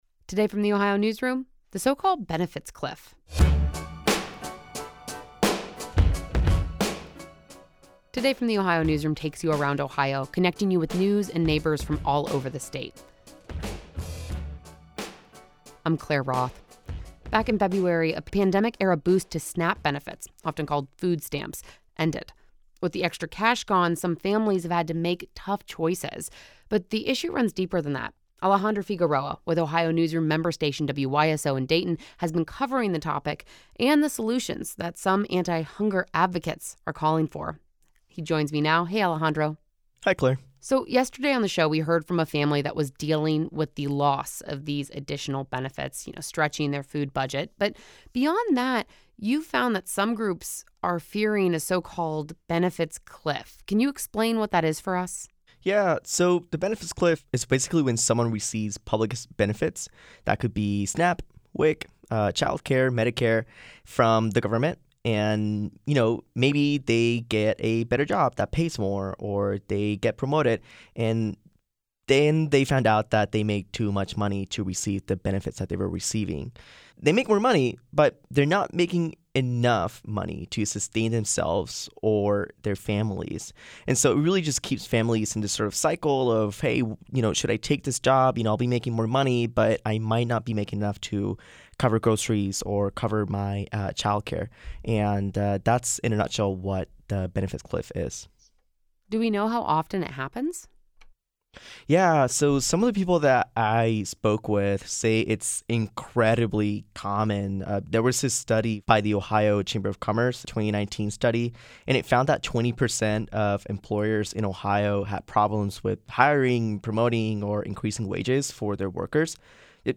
This conversation has been edited for brevity and clarity.